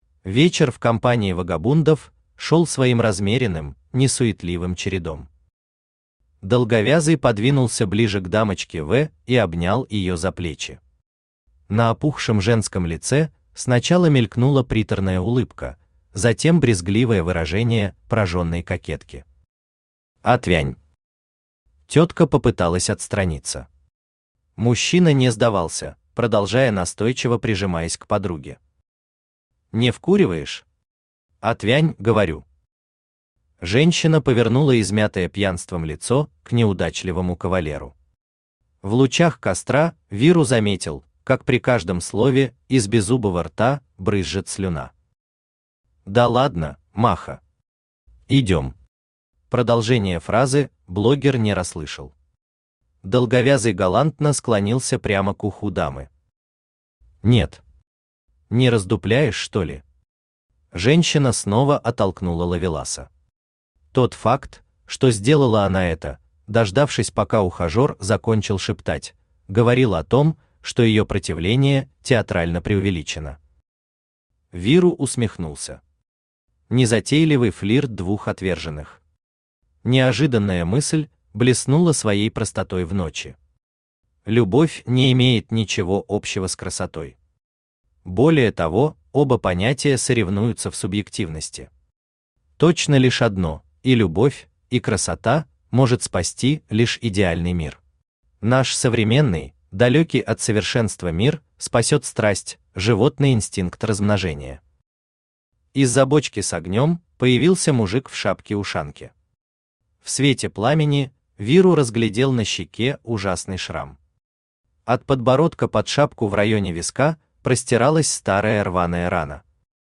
Аудиокнига Выход вниз. Факел | Библиотека аудиокниг
Факел Автор ШаМаШ БраМиН Читает аудиокнигу Авточтец ЛитРес.